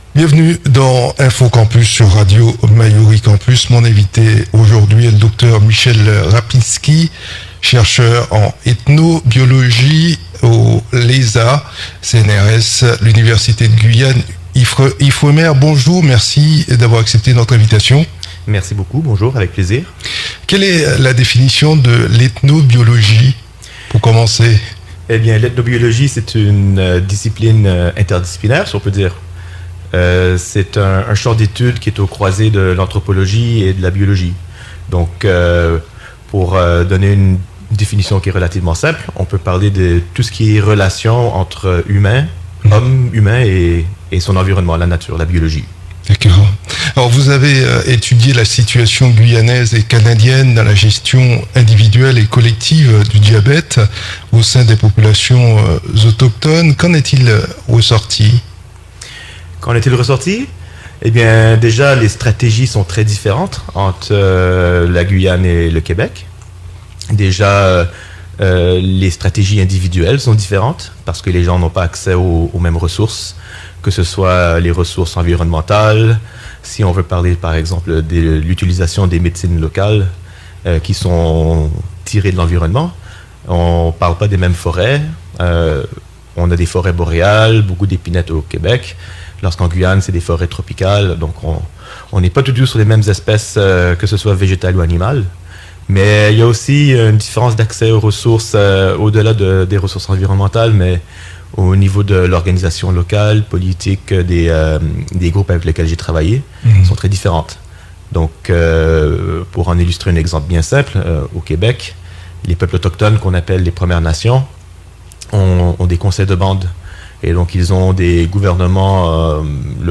Il était notre invité dans Info Campus.